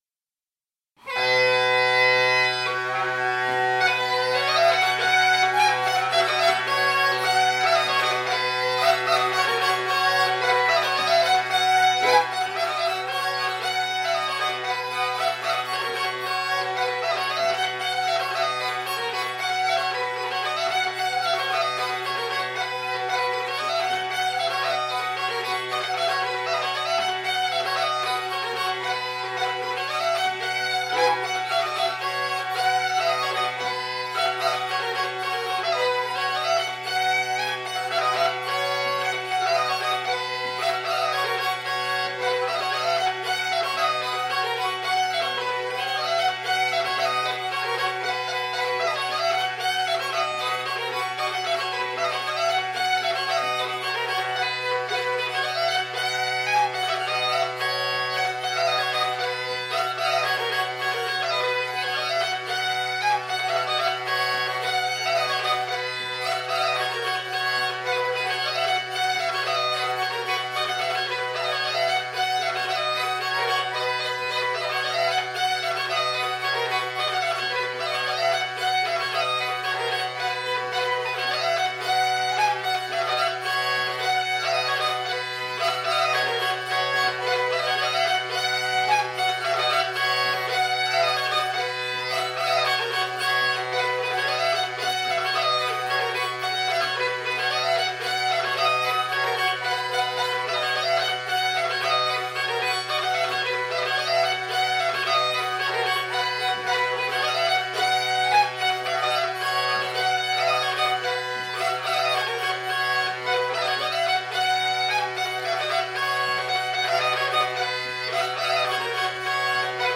5) Podkłady muzyczne do Przeglądu Tańca Tradycyjnego: wiwat,